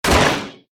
KART_Hitting_Wood_Fence_1.ogg